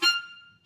Clarinet
DCClar_stac_F5_v3_rr1_sum.wav